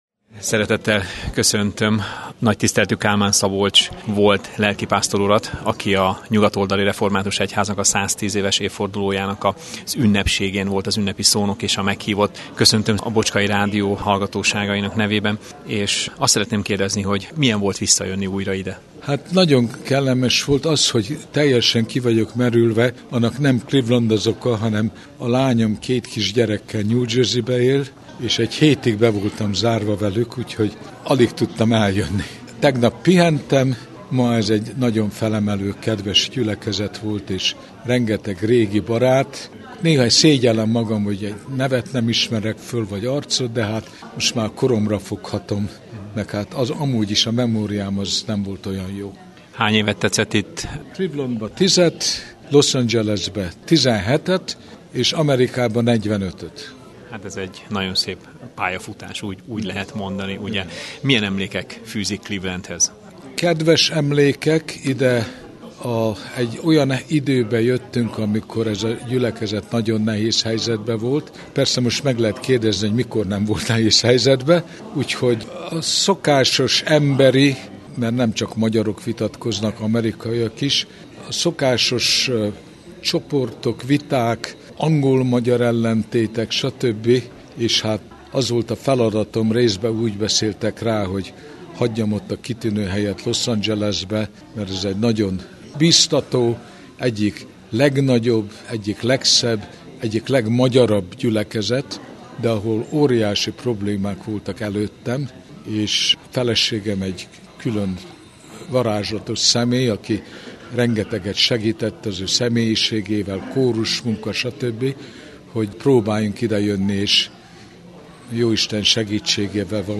A Bocskai Rádió részéről is részt vettünk néhányan ezen a rangos eseményen, amelynek a végén